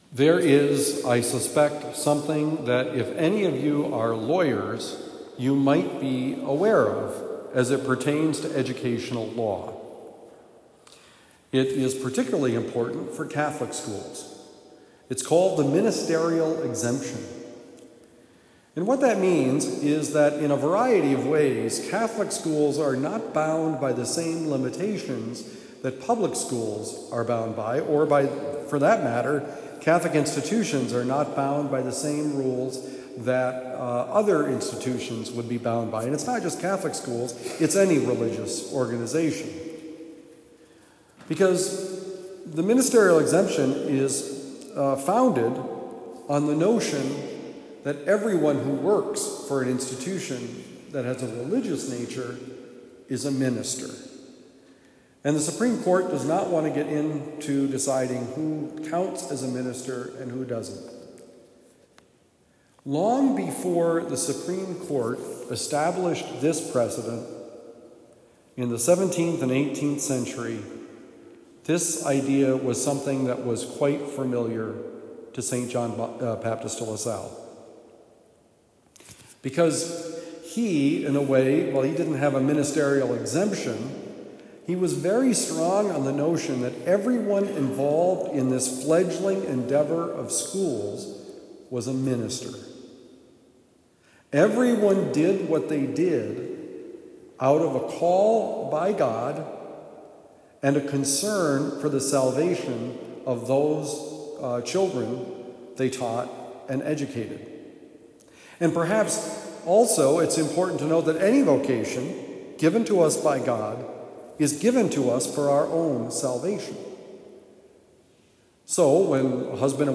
Homily given at Christian Brothers College High School, Town and Country, Missouri.